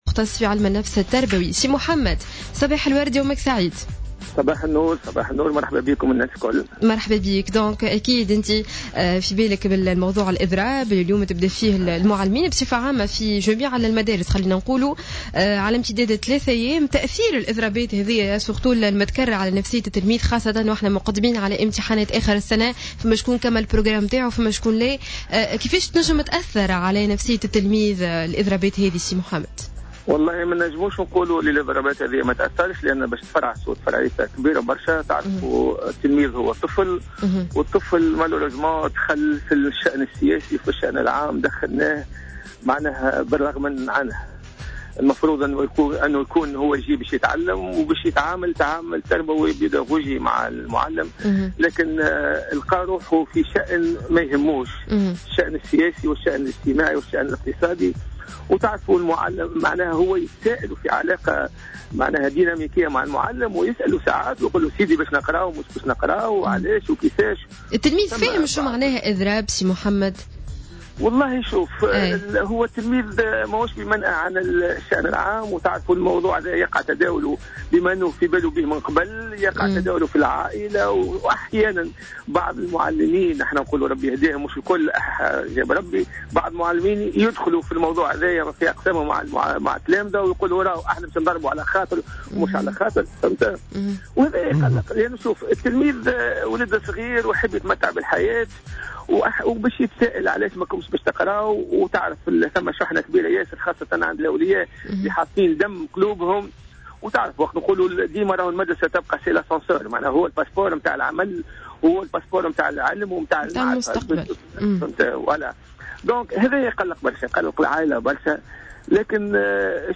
مختص في علم النفس التربوي : هذه تداعيات الإضرابات على التلاميذ